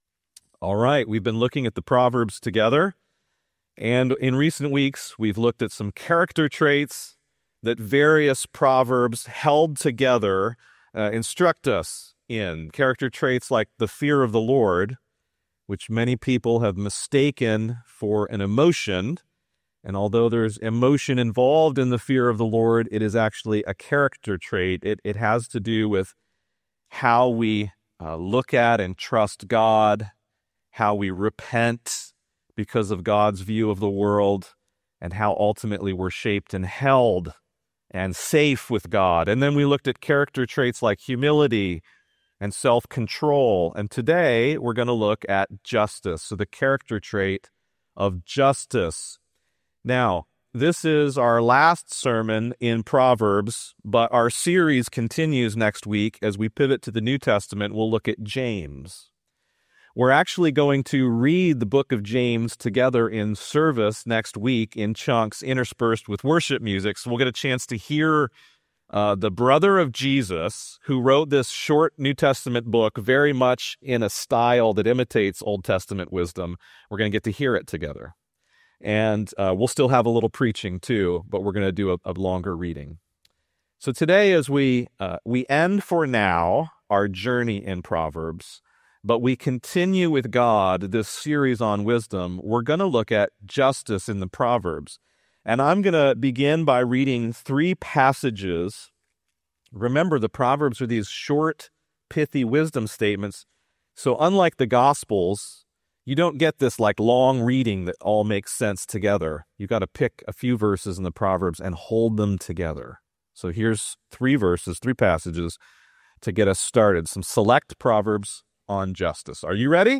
Covenant Church Doylestown Sermons The Wisdom Of Justice